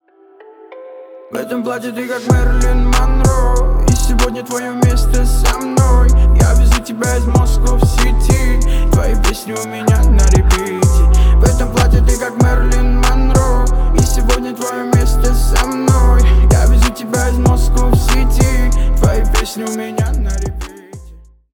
• Качество: 320 kbps, Stereo
Рэп и Хип Хоп
грустные